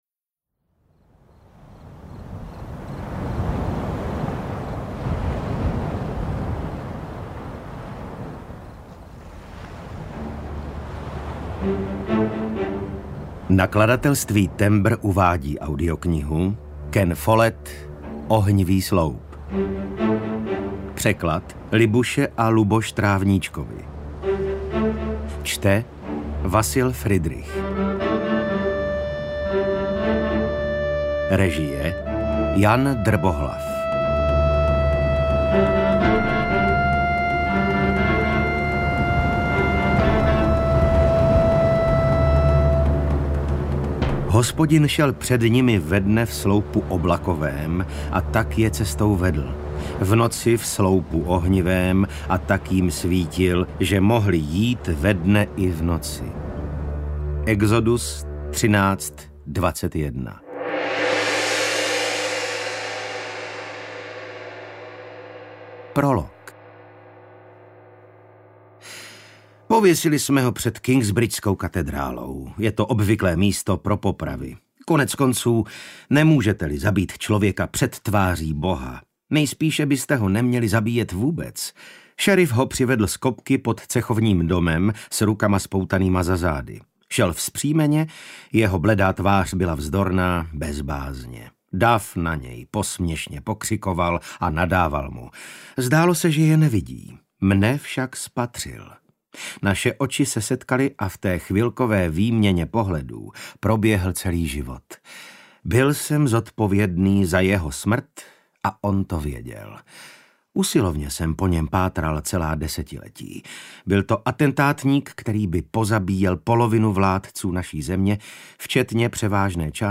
UKÁZKA Z KNIHY
Čte: Vasil Fridrich
audiokniha_ohnivy_sloup_ukazka.mp3